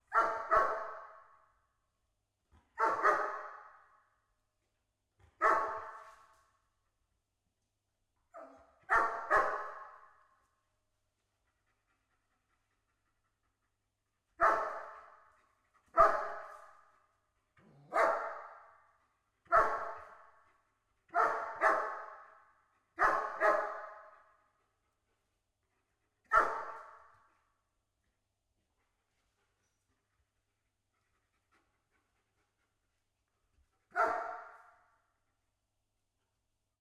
Dog_German_Shepherd_t15_Int_Bark_Slow_Distant_RSM191.ogg